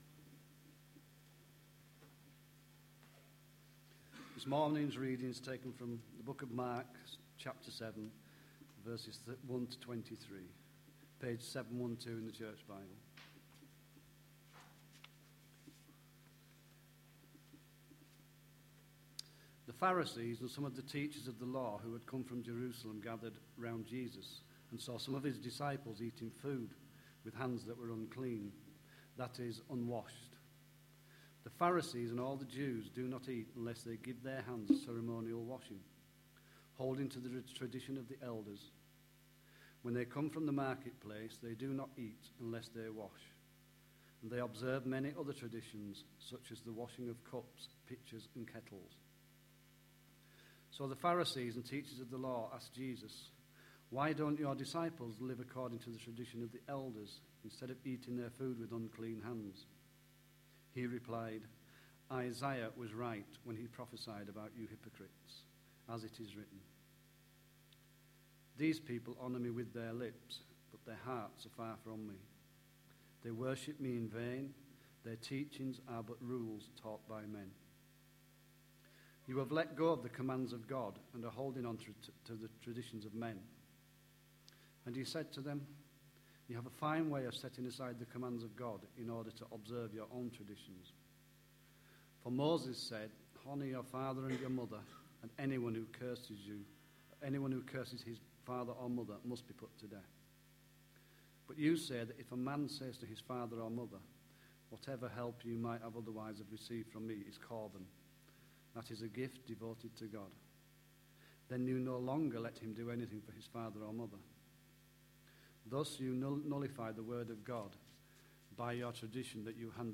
A sermon preached on 20th November, 2011, as part of our Mark series.